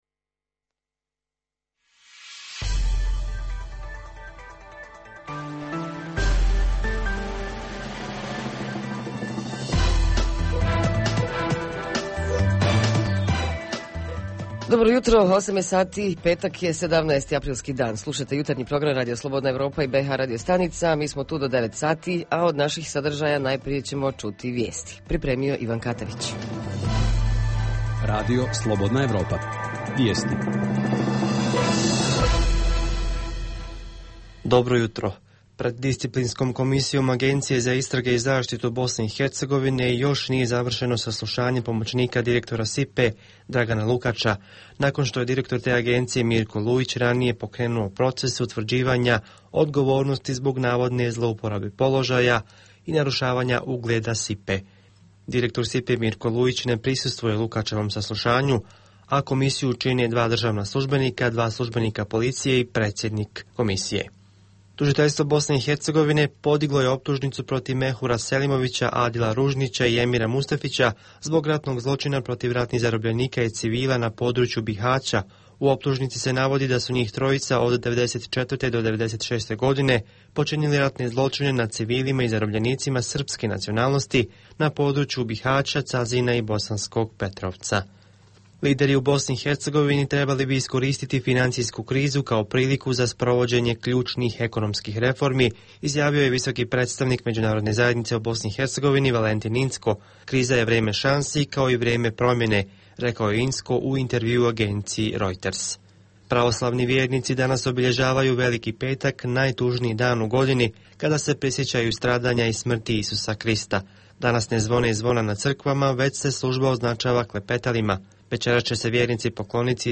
Jutarnji program za BiH koji se emituje uživo ovog jutra istražuje da li su počele pripreme za turističku sezonu. Reporteri iz cijele BiH javljaju o najaktuelnijim događajima u njihovim sredinama.
Redovni sadržaji jutarnjeg programa za BiH su i vijesti i muzika.